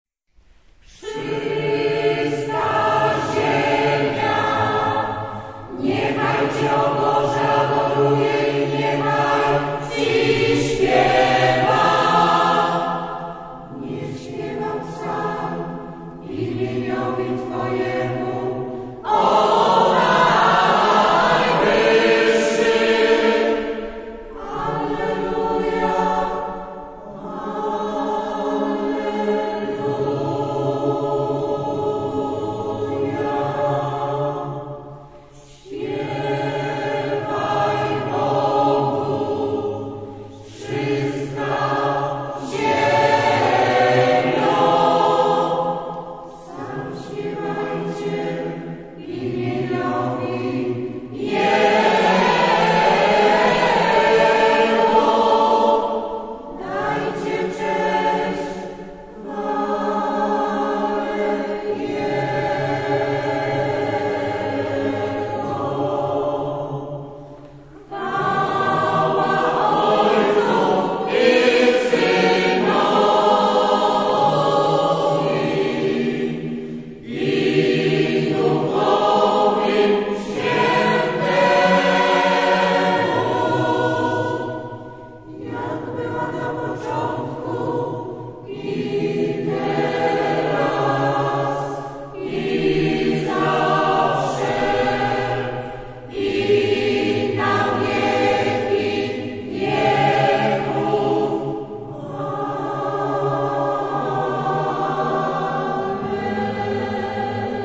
Chór Diecezji śląsko - łódzkiej  „MARIAWITA”